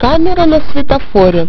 Вот несколько примеров, из последнего (и единственного) файла озвучки встречаются очень забавные фразы которые я в живую ни разу не слышал от РД и еще, т.к. включу в прогу поддержку редактирования звуковых файлов, дальнейшее обсуждение в профильной ветке.